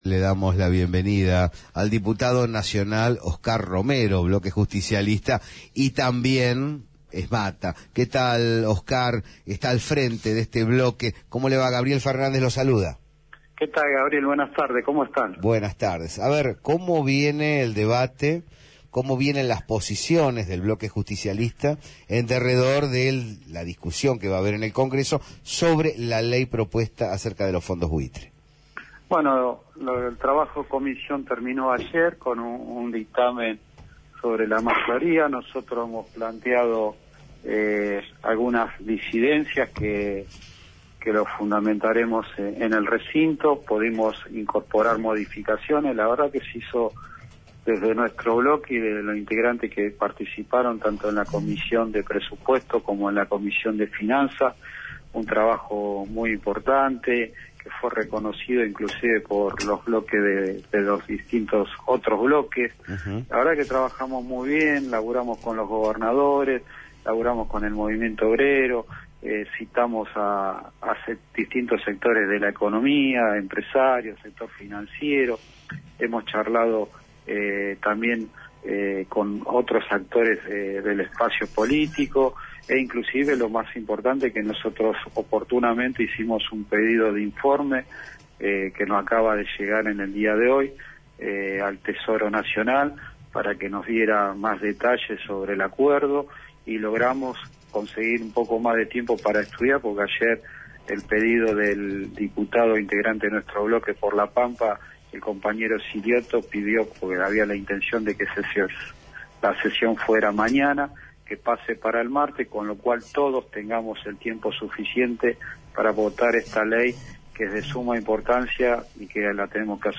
Óscar Romero, diputado nacional por el Bloque Justicialista y dirigente sindical de SMATA, fue consultado en Terapia de Grupo de La Señal acerca de la postura del flamante espacio que integra en torno al debate por el proyecto de acuerdo con los fondos buitre.